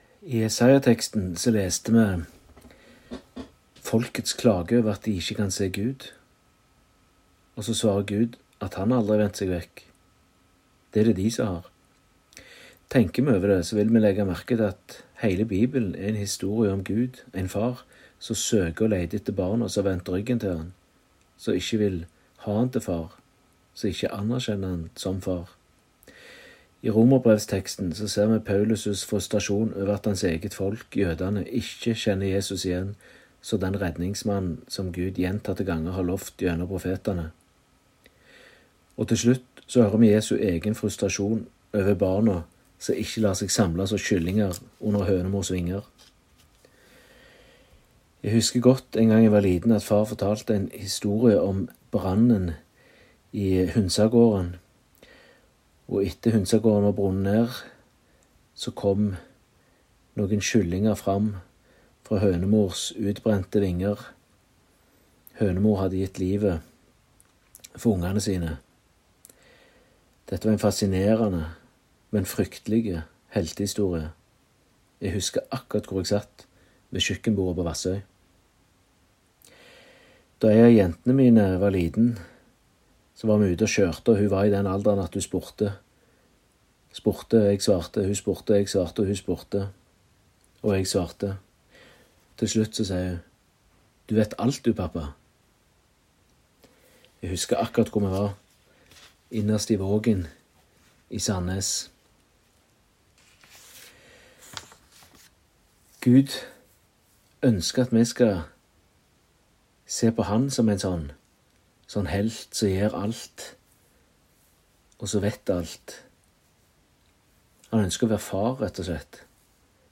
Utdrag fra talen (Hør hele talen HER ) Tekstene I dagens tekster kan vi lese mye frustrasjon over barn som ikke anerkjenner sin egen far.